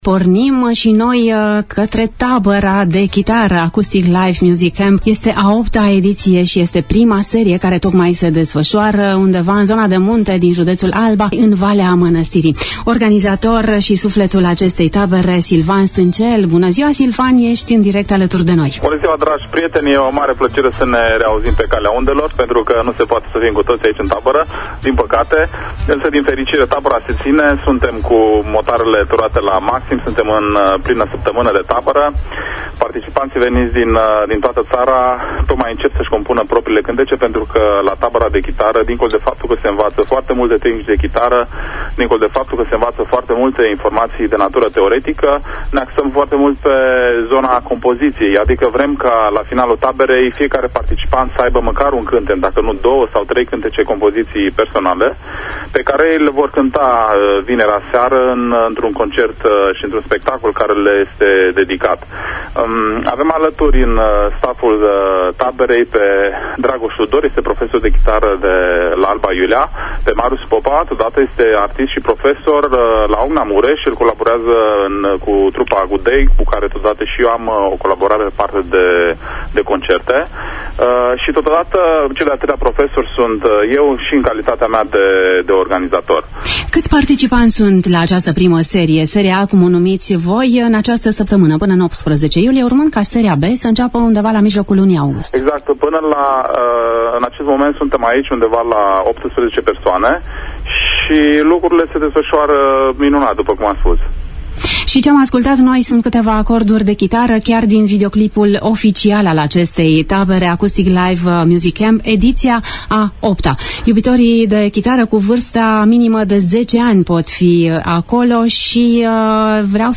“Pulsul zilei” a poposit în tabăra de chitară zilele trecute într-una din pause, cu întrebările la noi, la care s-au adăugat zecile de întrebări cărora li se găseşte răspuns în campul musical din Apuseni.